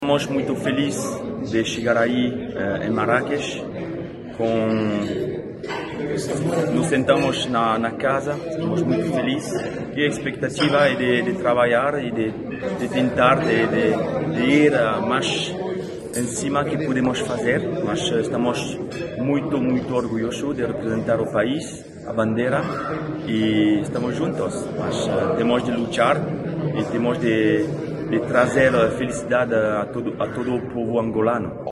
Patrice Boumel, técnico da nossa selecção, fala da disponibilidade e felicidade do grupo à chegada ao palco da competição.